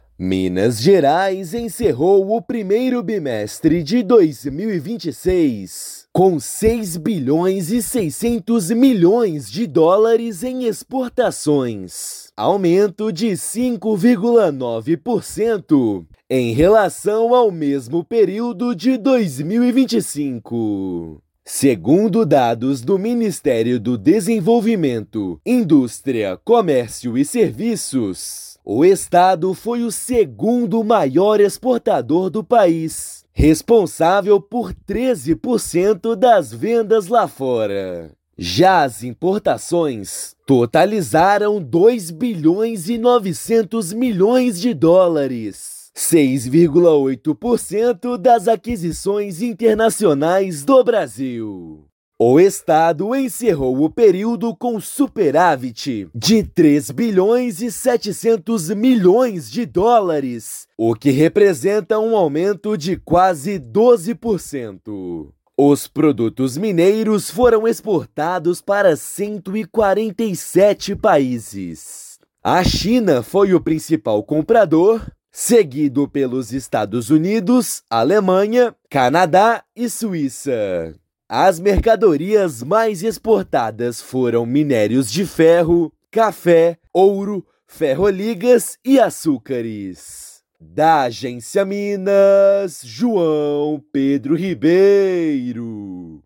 Com aumento nas vendas de ouro e de ferro, estado é o segundo maior exportador do Brasil no ano. Ouça matéria de rádio.